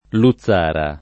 [ lu ZZ# ra ]